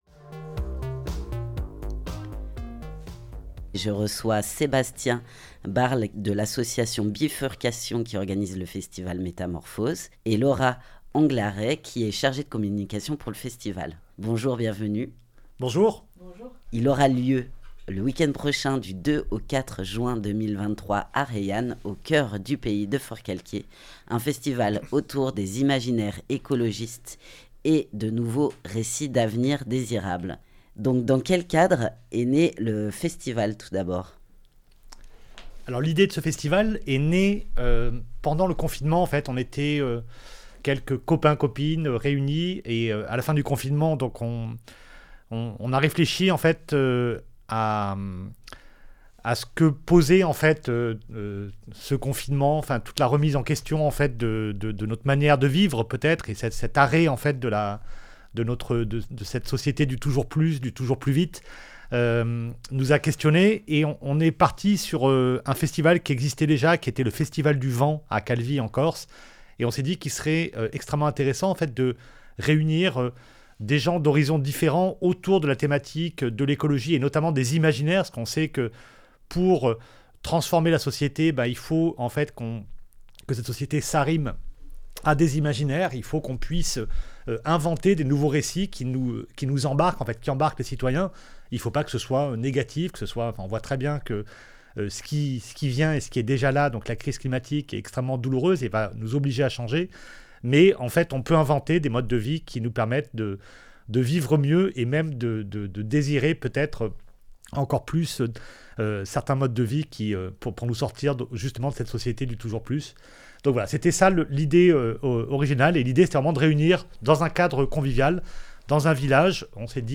étaient les invités de la matinale du 26 Mai 2023. Le festival Métamorphoses, croisant art et écologie, se tiendra pour sa deuxième édition dans le village de Reillanne (04) du 2 au 4 juin.